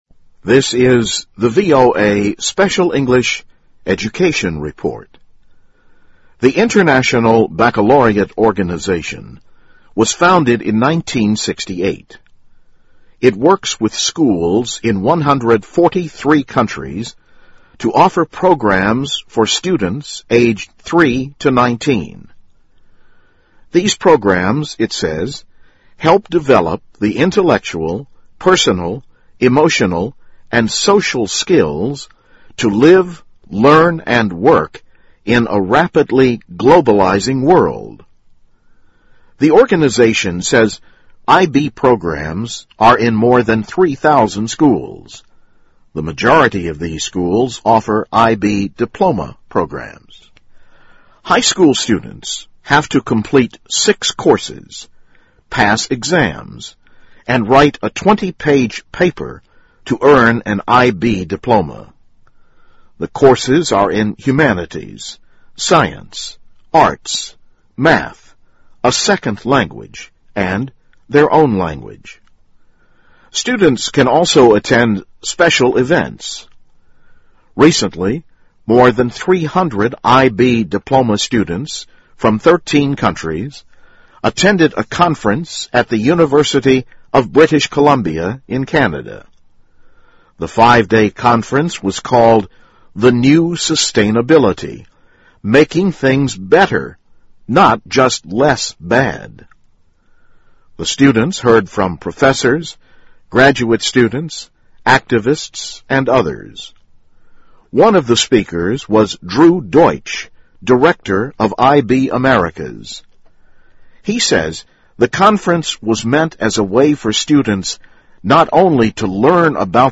VOA慢速英语2012 Education Report - IB Program Aims to Form 'Students of the World' 听力文件下载—在线英语听力室